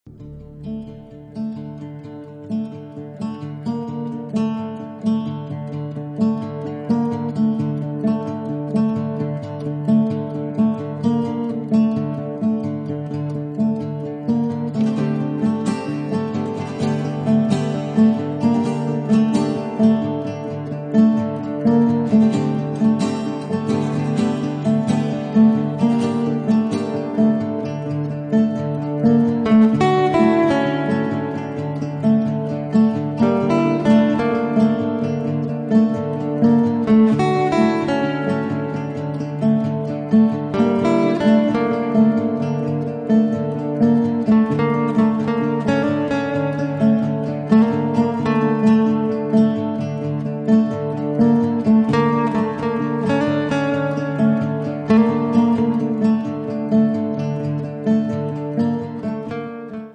Accordeon
Violino
Chitarra elettrica
Basso
Batteria e percussioni
Un percorso impregnato di Mediterraneo